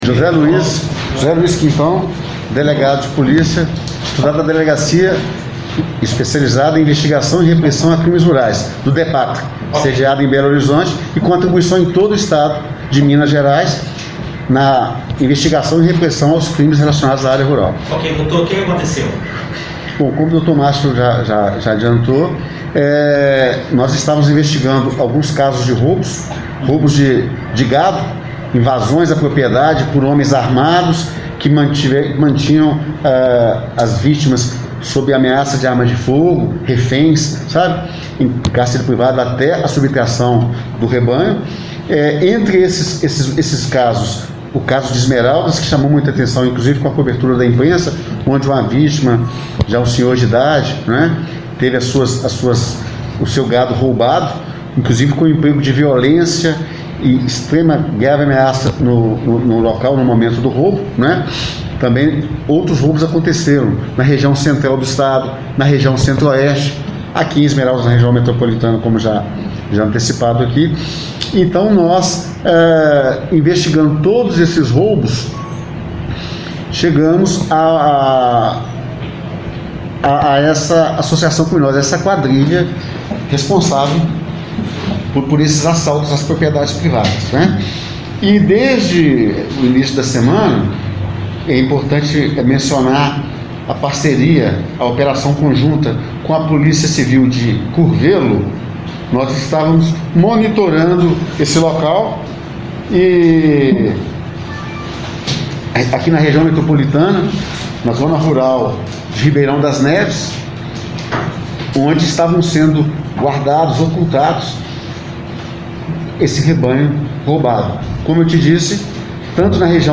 Sonora da coletiva